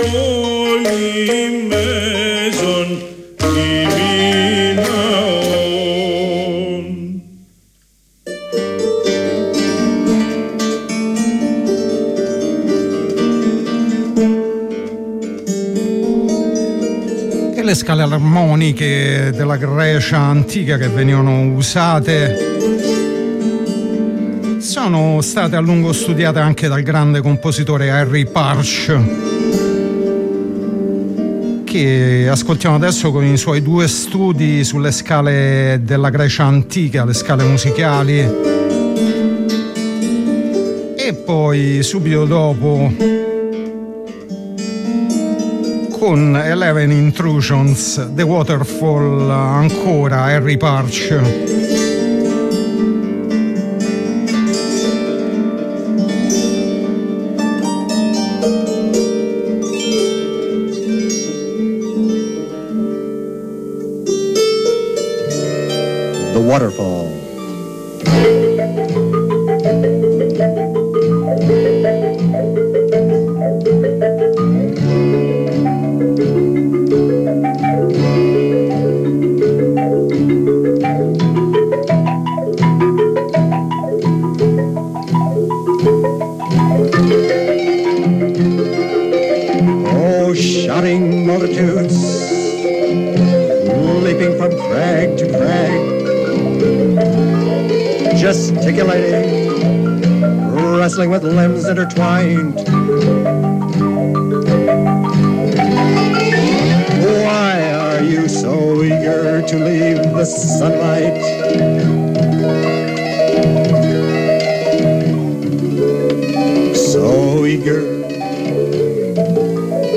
neo psichedelia | Radio Onda Rossa